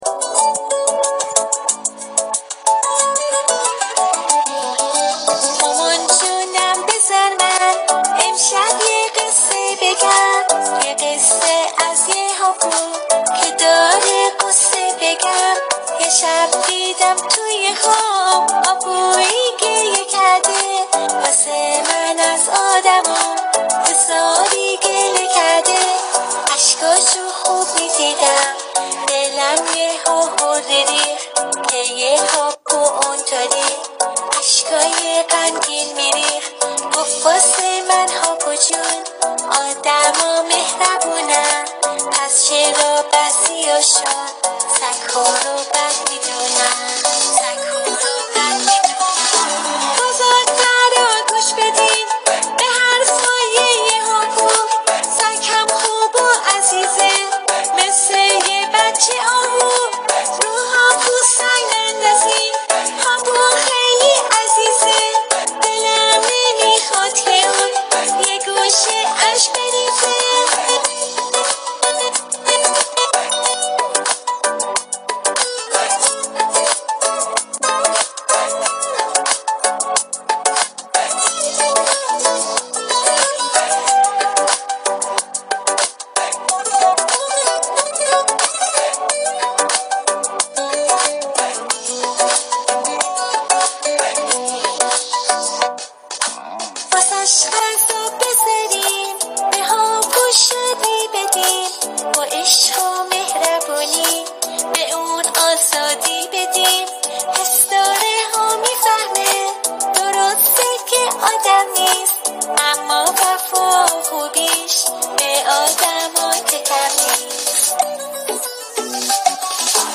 dog.mp3